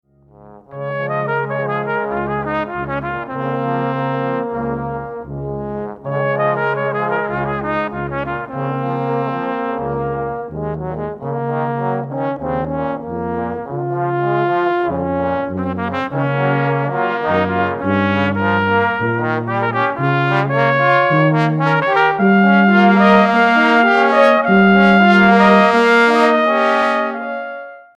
40 - Copier - Ensemble de cuivres Epsilon